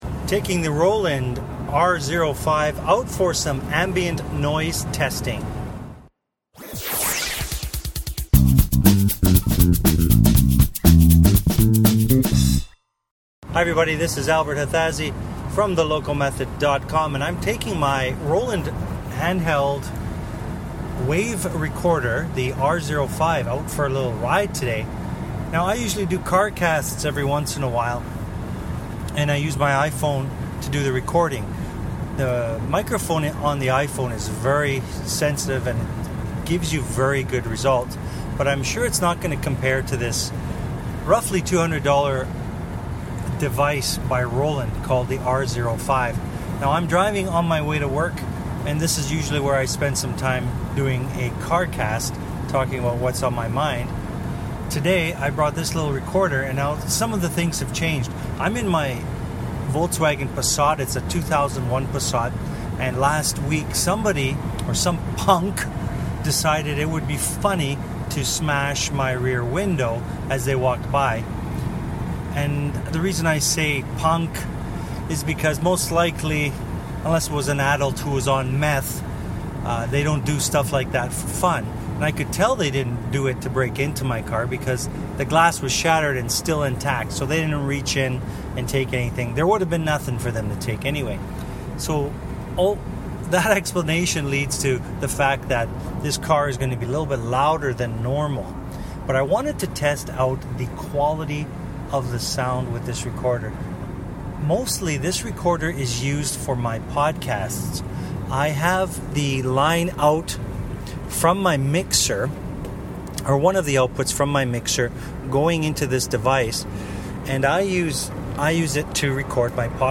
Roland R-05 Ambient Noise Test (Car Cast)
Have a listen to what the Roland R-05 recorder captures as I take it for a ride in my Passat. My rear window was smashed in a random act of dumbness by a never to be found vandal so the road noise is louder than it normally would be.